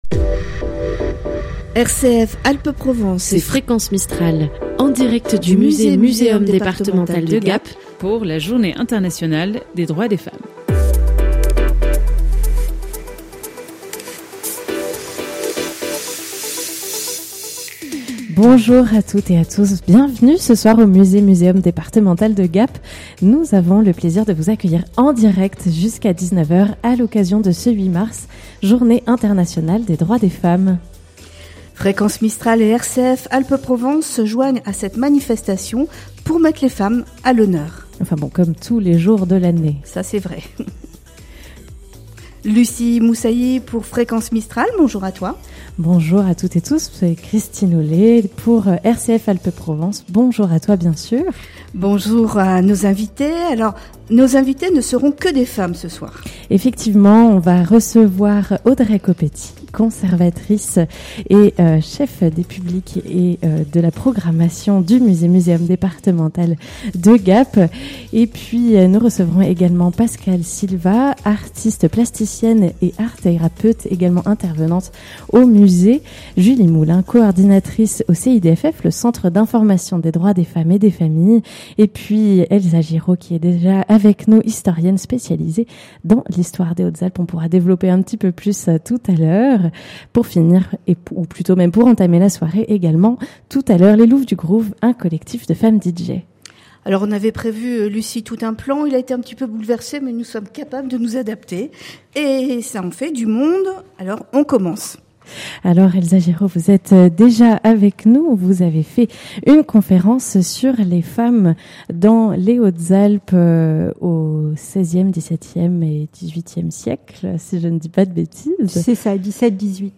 Direct 8 Mars au musee.MP3 (55.24 Mo)
Une émission à 99% féminine, avec des invitées toutes plus intéressantes les unes que les autres.